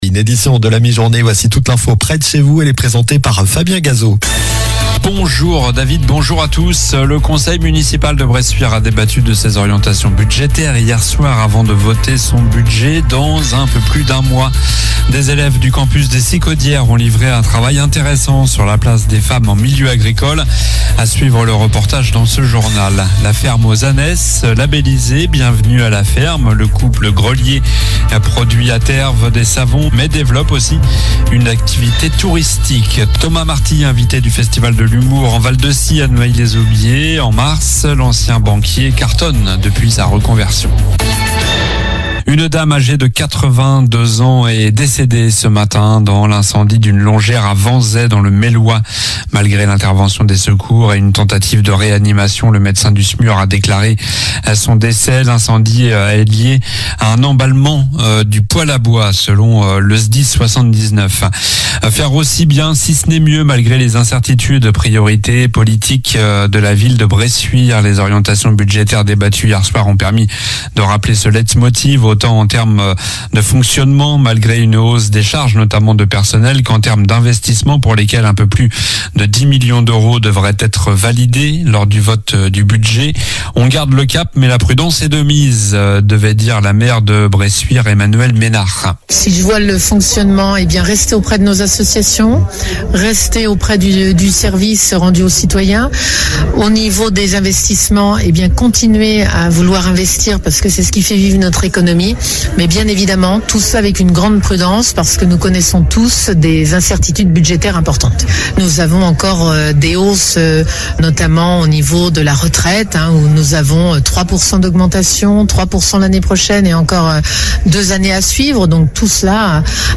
Journal du mardi 18 février (midi)